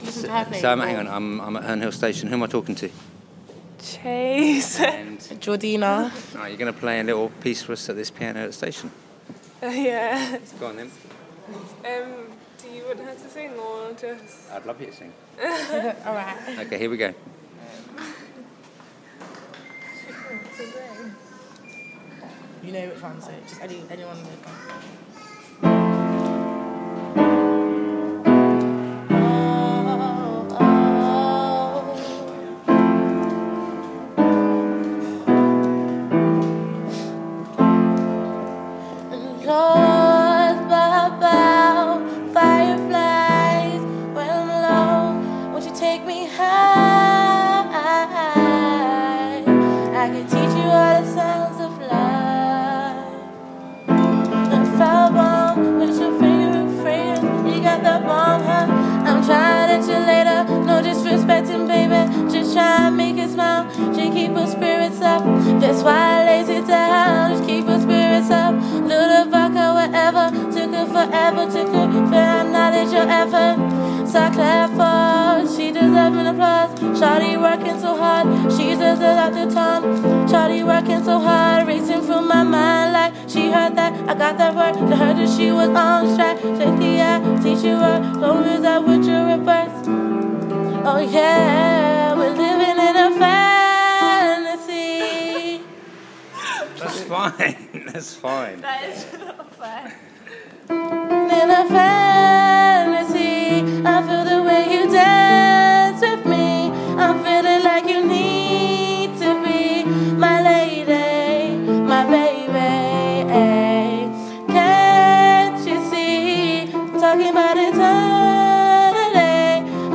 Herne Hill Piano
Bumped into these two at Herne Hill Station when they were playing the piano. Impromptu loveliness. Love the bit when they muck up in the middle.